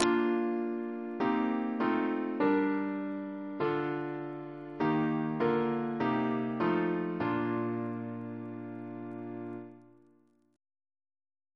Single chant in B♭ Composer: Donald B. Eperson (1904-2001) Reference psalters: ACB: 15